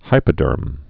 (hīpə-dûrm)